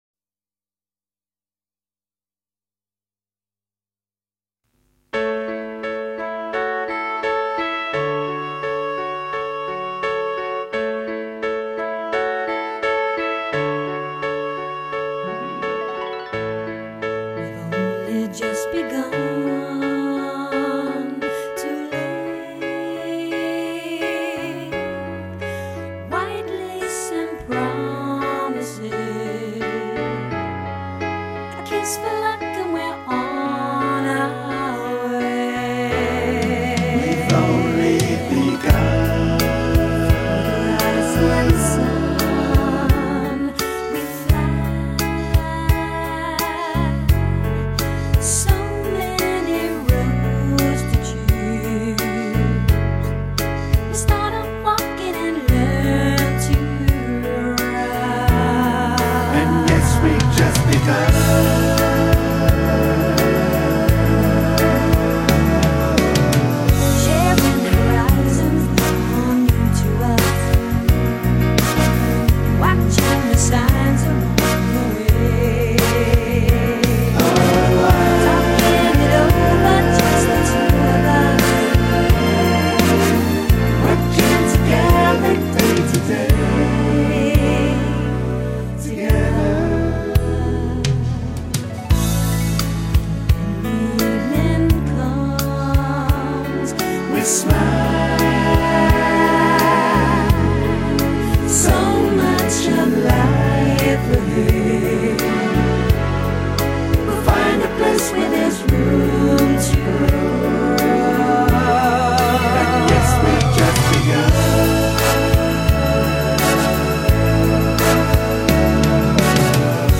FEMALE VOCALIST